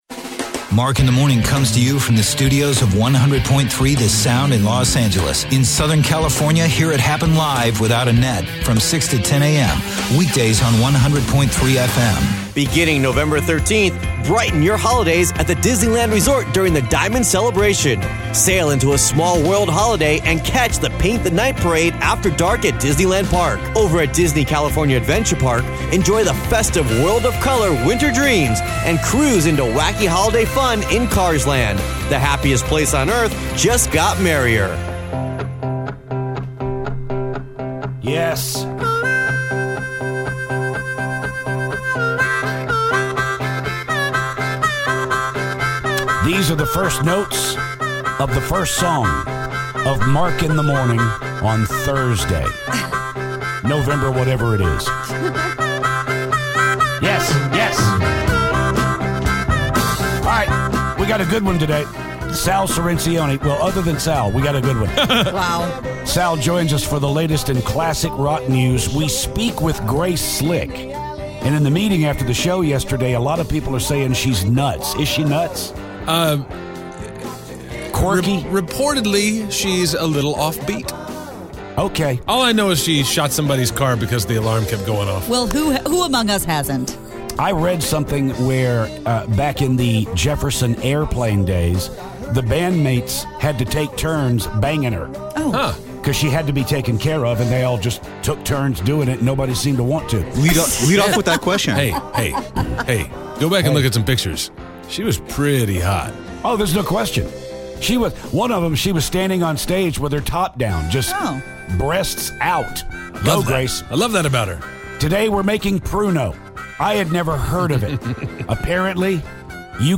The legendary Grace Slick calls